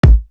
Latin Thug Kick 2.wav